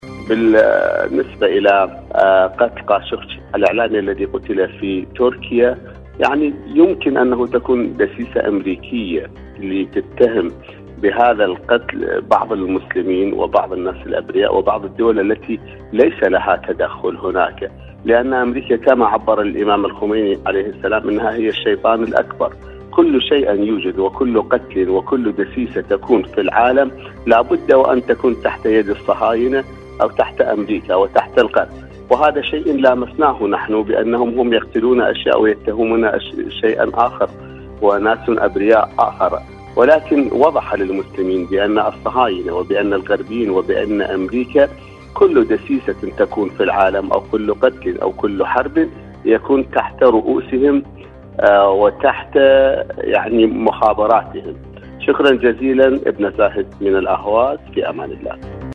برنامج : حدث وحوار/مشاركة هاتفية